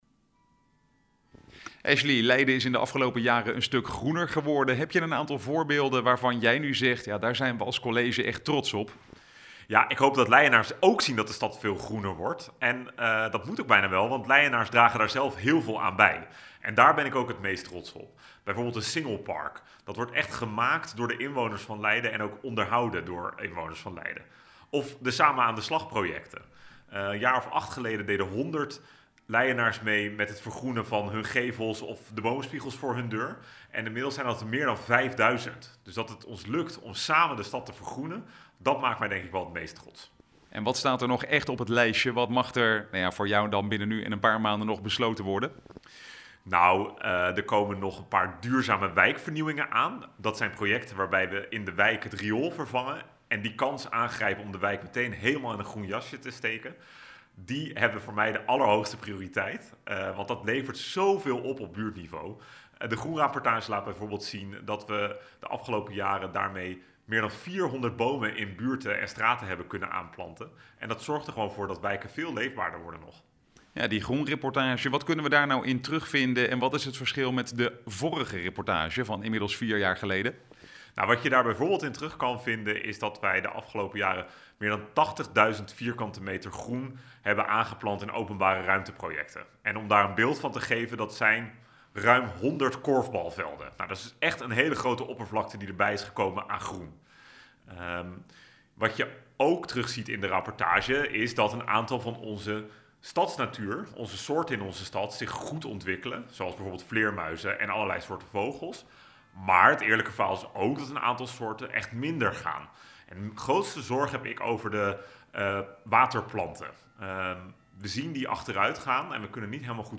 Interview Leiden Maatschappij Nieuws Politiek